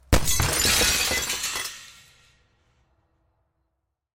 随机 " 霓虹灯管砸碎爆炸，玻璃碎片粉碎
描述：霓虹灯管粉碎爆破粉碎玻璃碎片
Tag: 碎片 玻璃 爆炸 粉碎 粉碎